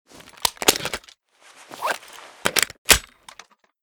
bizon_reload.ogg